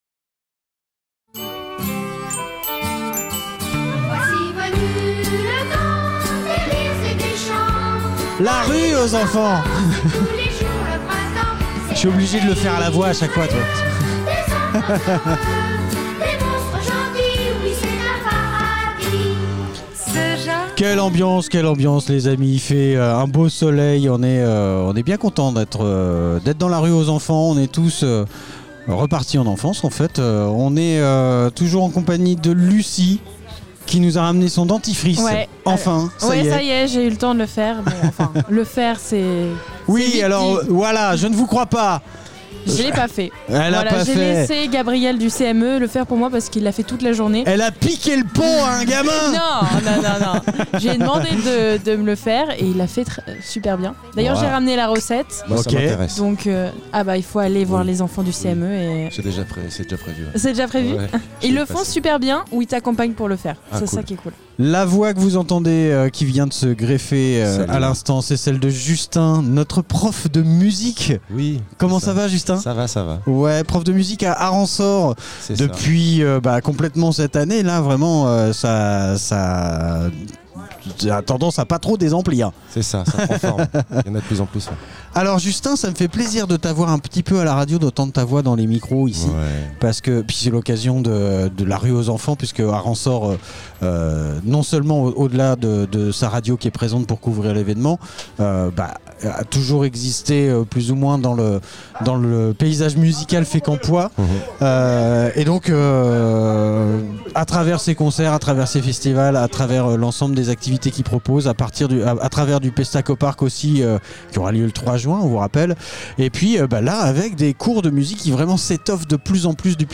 La rue aux enfants Interview fécamp associations association rue aux enfants